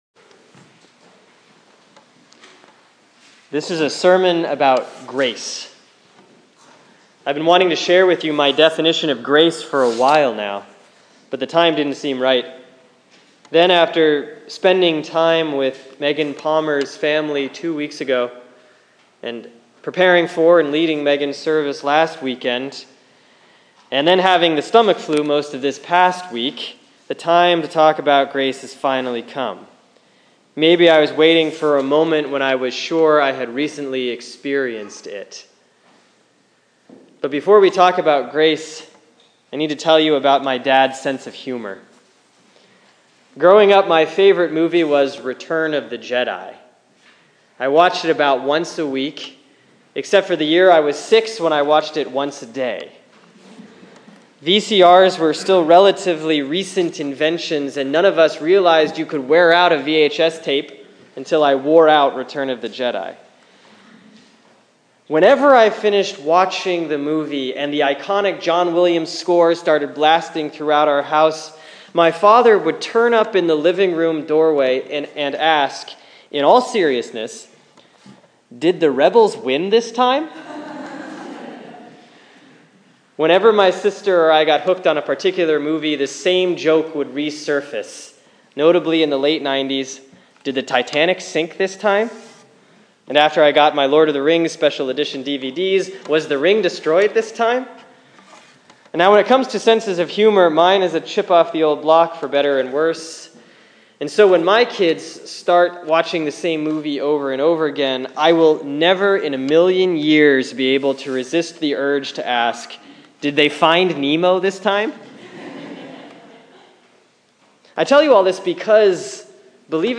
Sermon for Sunday, February 28, 2016 || Lent 3C || Luke 13:1-9